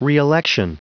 Prononciation du mot reelection en anglais (fichier audio)
Prononciation du mot : reelection